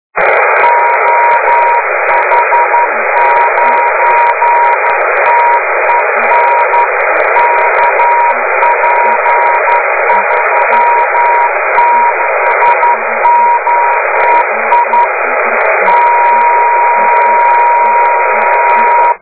Les fichiers à télécharger sont compressés au format MP3 à 1ko/sec, ce qui explique la très médiocre qualité du son.
bande 10 m, balise OH9TEN sur 28,266, locator KP36oi, CW